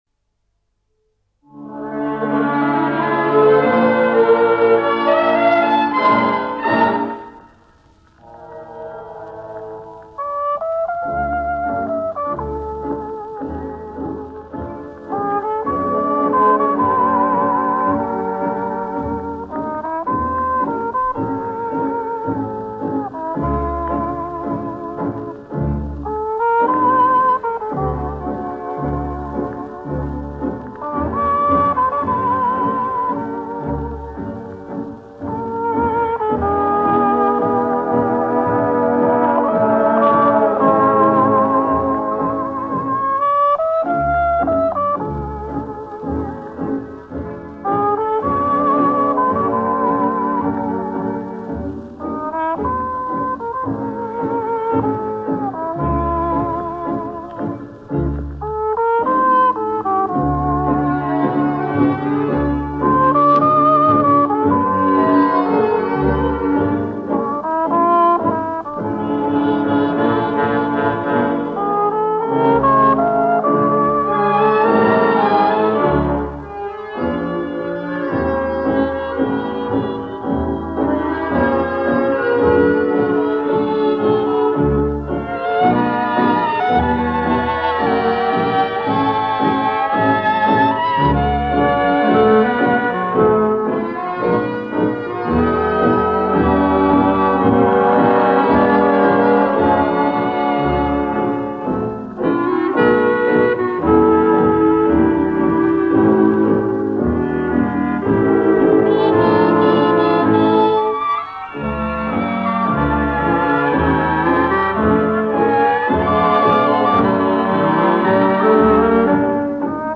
Соло на трубе.